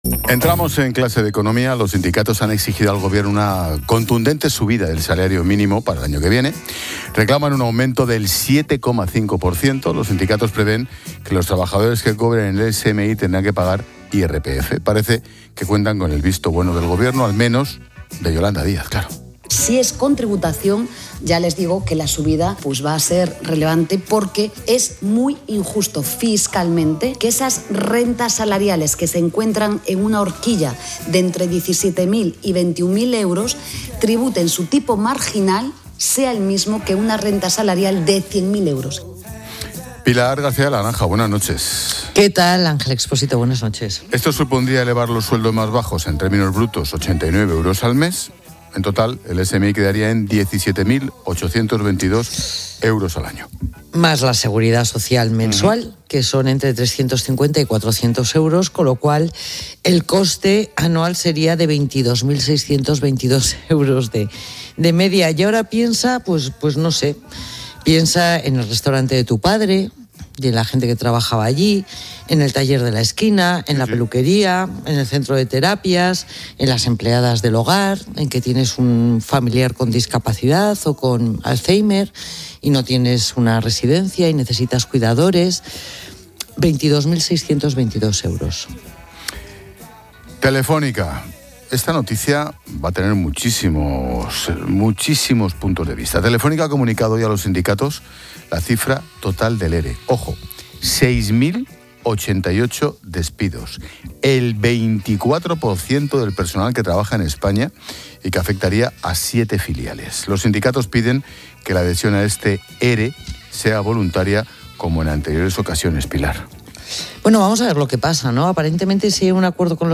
Entrevista fiscalista